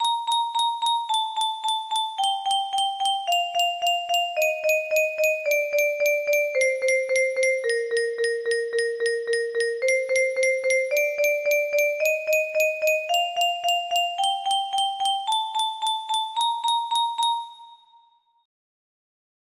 Amy music box melody